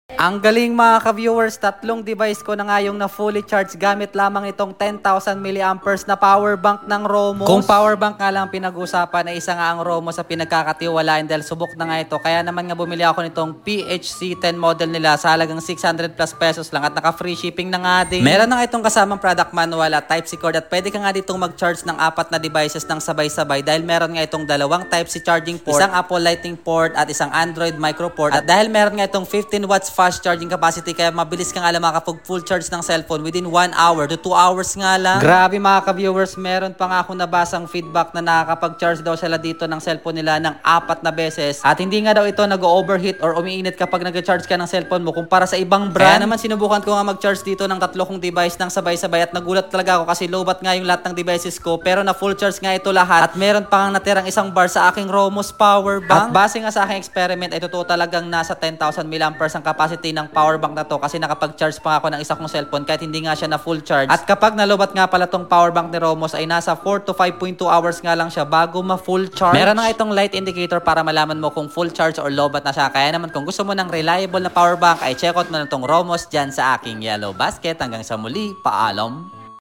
Romoss PHC10 10,000 mAh Powerbank sound effects free download